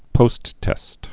(pōsttĕst)